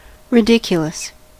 Ääntäminen
UK : IPA : /rɪ.ˈdɪk.jʊ.ləs/ US : IPA : [rɪ.ˈdɪk.jʊ.ləs]